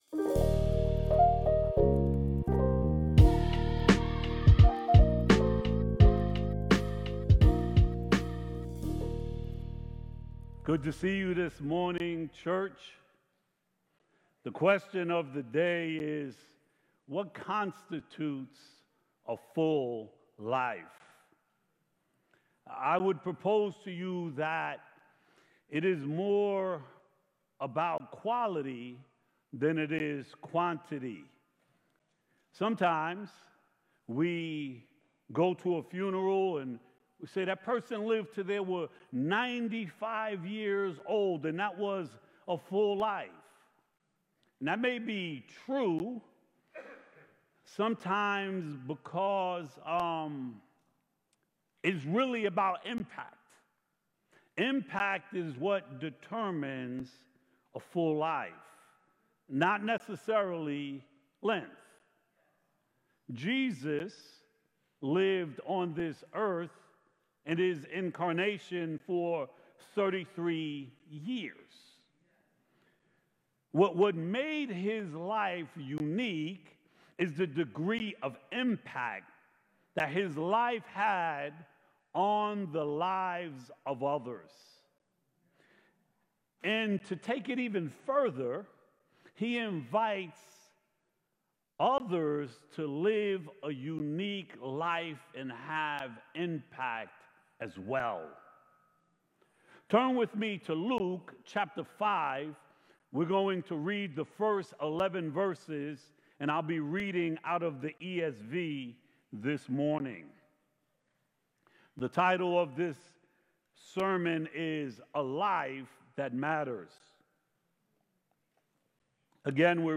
Sermons from Light of the World Church in Minisink Hills, PA